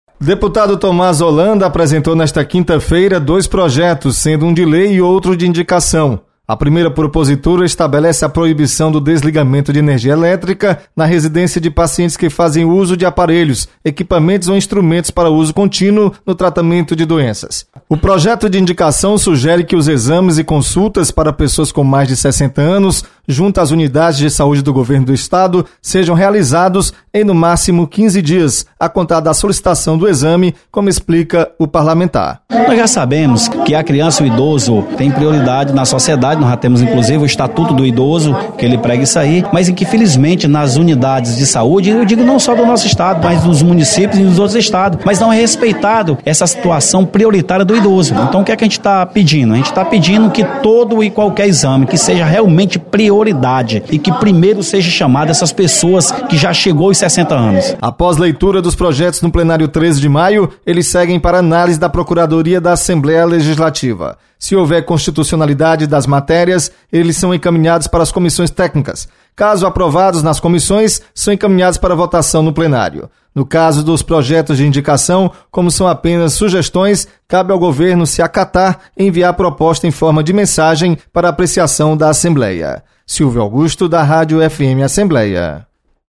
Deputado Thomaz Holanda apresenta dois projetos nesta quinta-feira. Repórter